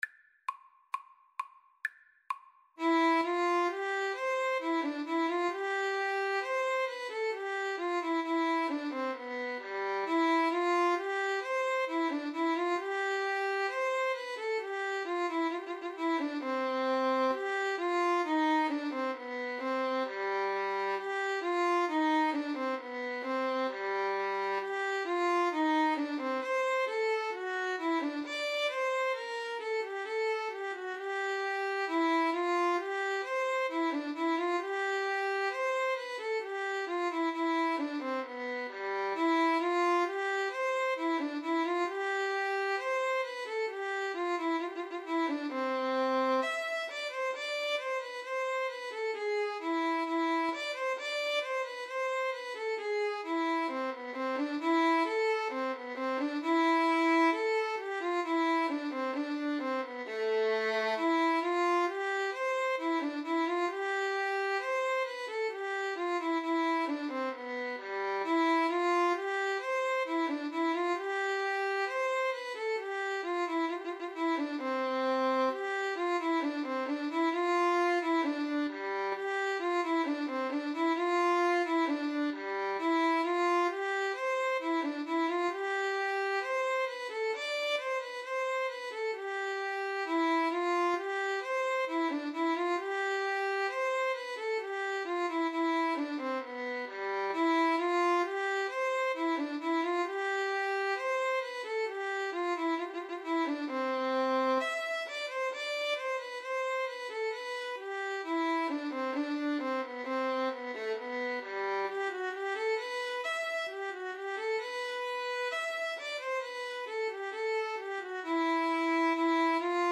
2/2 (View more 2/2 Music)
~ = 100 Allegretto =c.66
Classical (View more Classical Violin-Viola Duet Music)